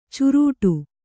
♪ curūṭu